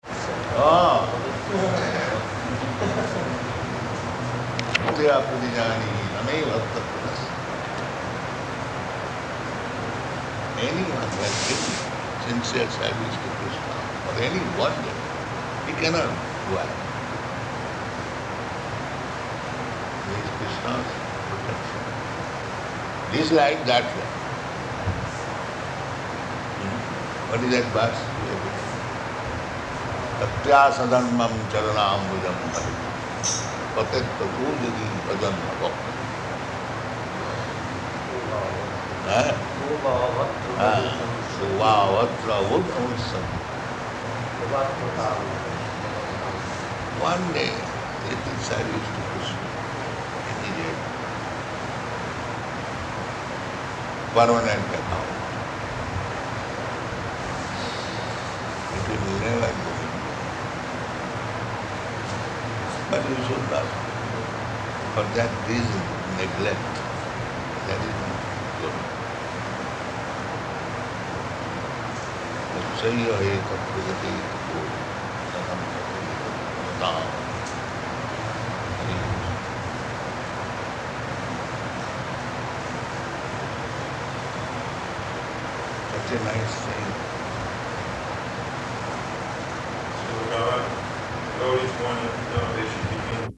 Room Conversation [partially recorded]
Location: Vṛndāvana